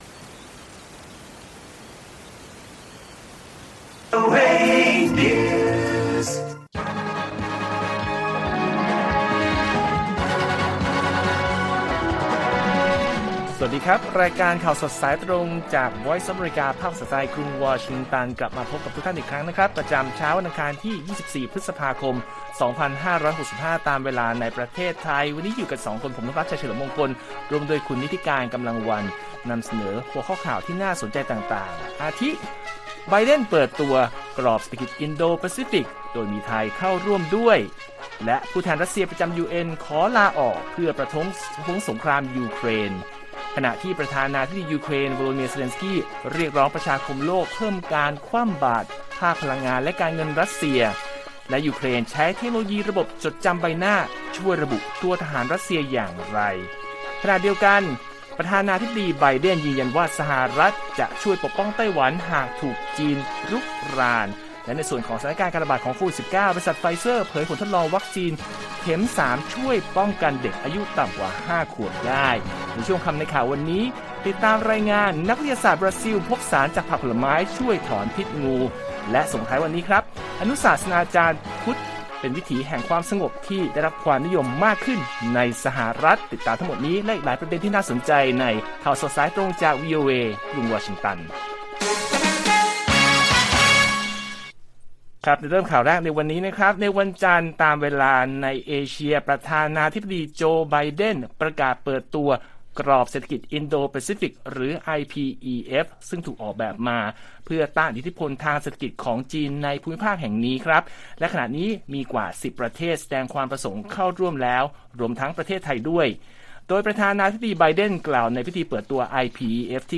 ข่าวสดสายตรงจากวีโอเอ ไทย อังคาร 24 พ.ค. 2565